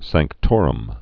(săngk-tôrəm)